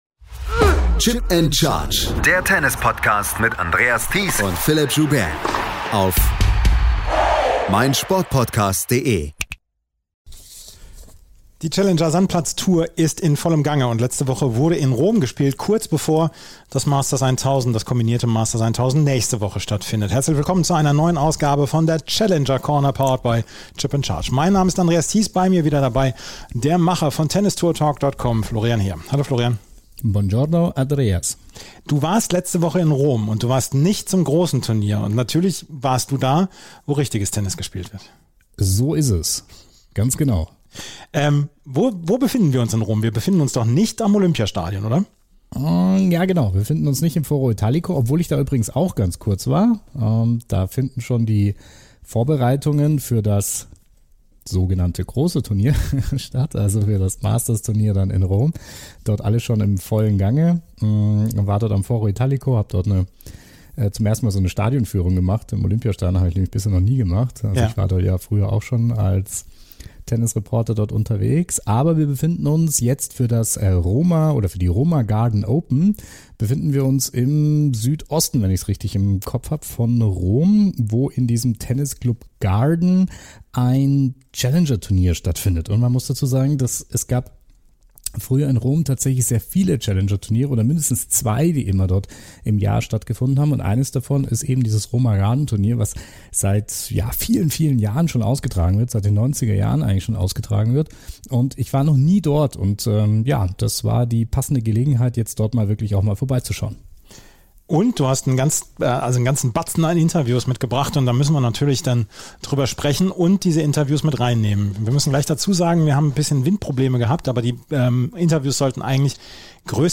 Interview ~ Tennis Podcast